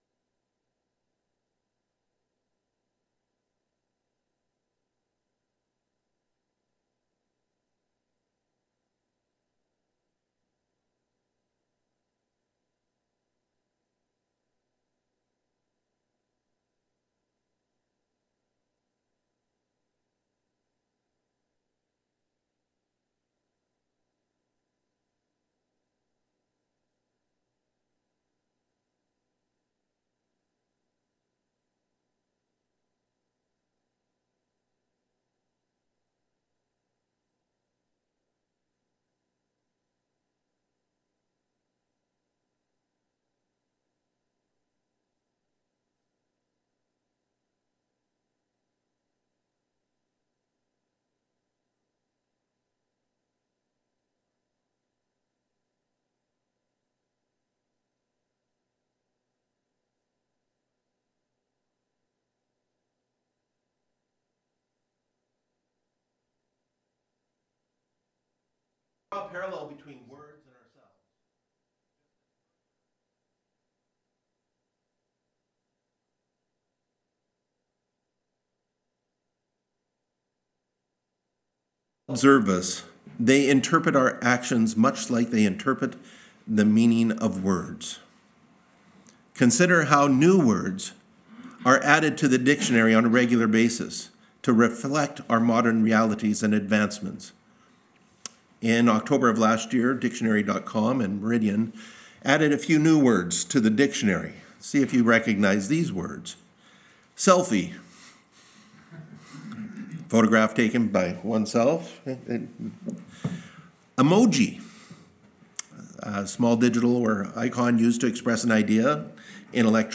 Series: Miscellaneous Sermons & Testimonies